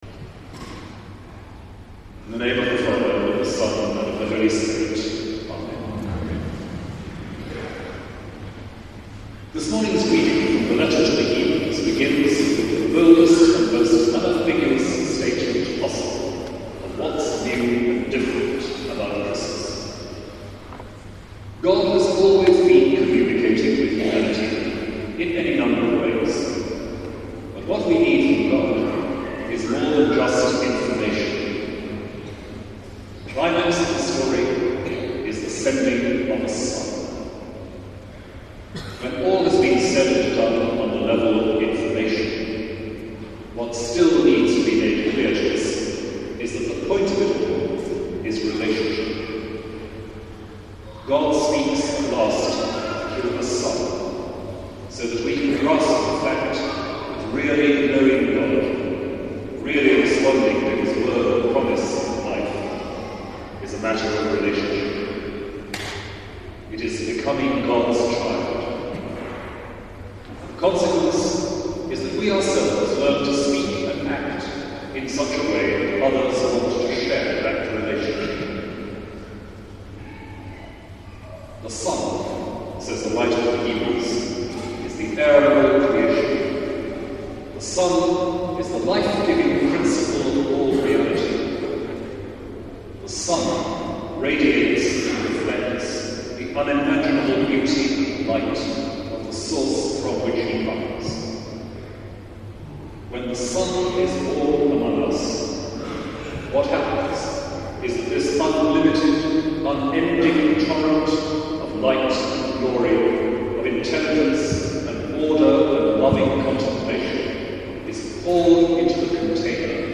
Preaching at Canterbury Cathedral on Christmas Day 2009 (full transcript below), Dr Williams says that Christmas teaches us that a truly fulfilled existence means accepting joyfully our dependence on one another.